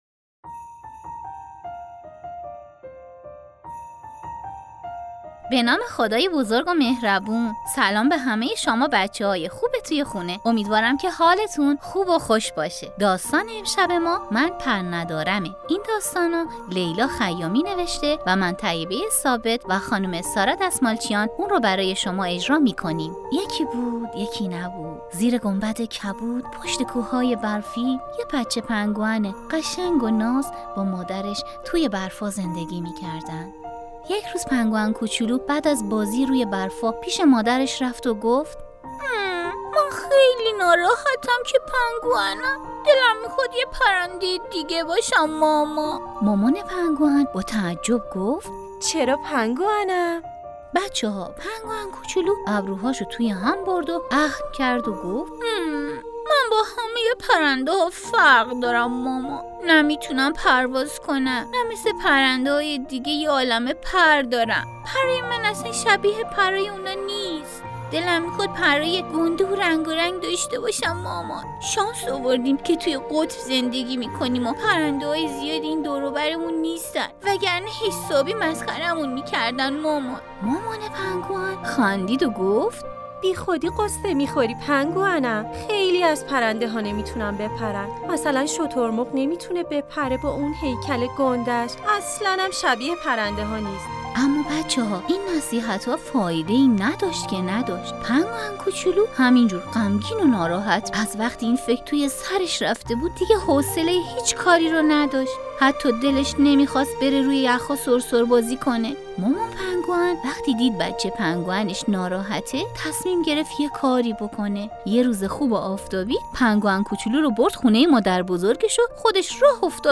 کودک / چند رسانه‌ای